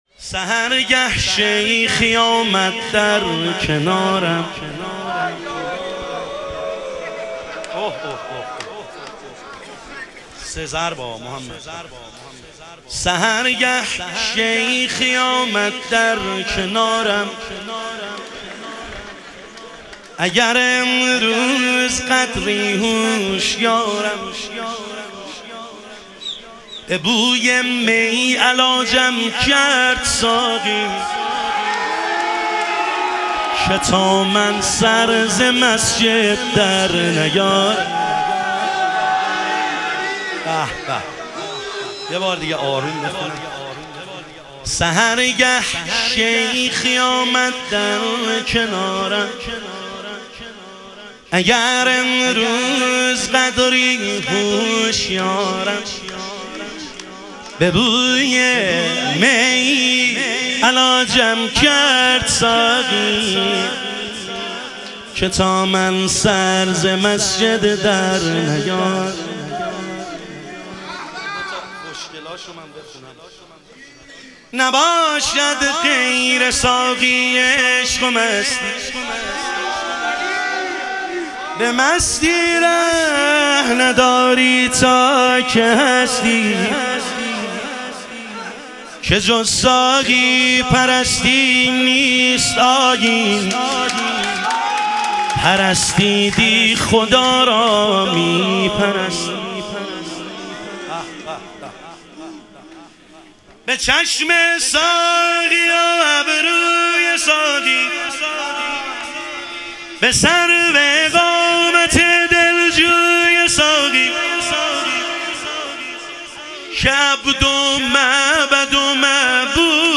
ظهور وجود مقدس حضرت امیرالمومنین علیه السلام - شور